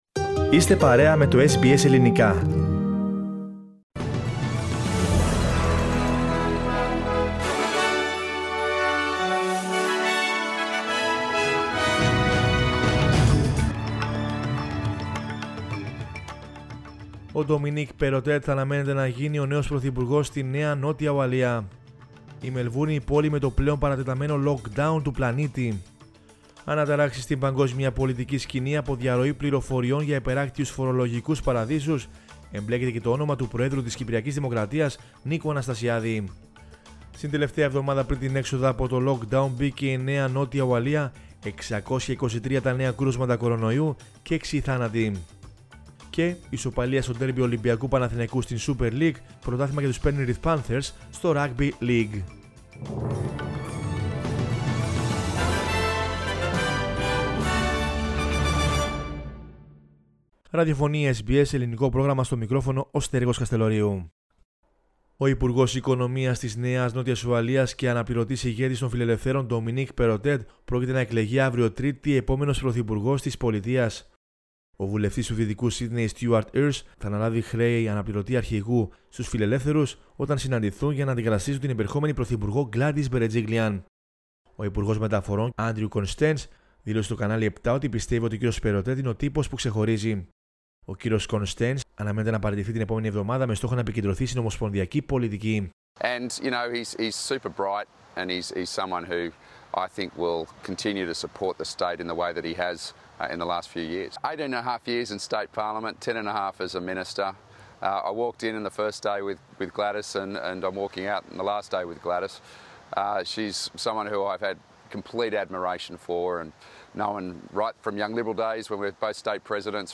News in Greek from Australia, Greece, Cyprus and the world is the news bulletin of Monday 4 October 2021.